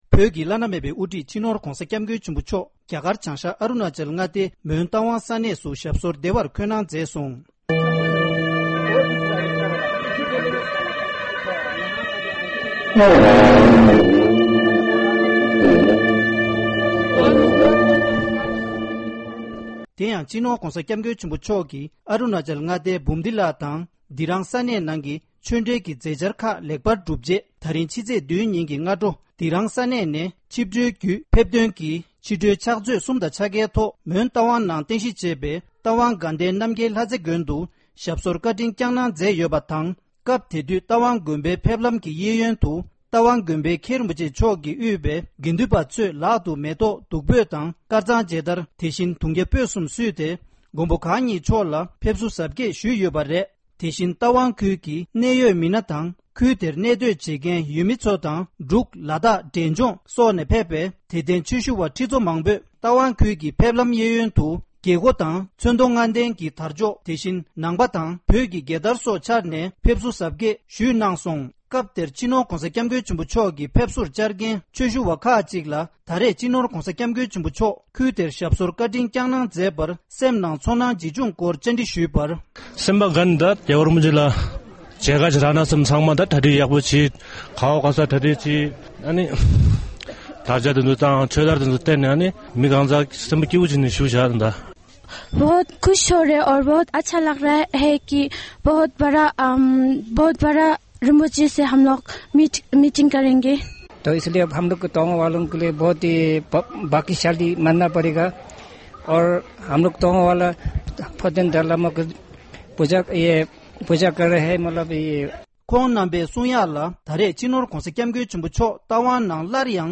ཞིབ་ཕྲ་འདི་གའི་གསར་འགོད་པས་ས་གནས་ནས་གནས་ཚུལ་བཏང་བར་གསན་རོགས།
སྒྲ་ལྡན་གསར་འགྱུར།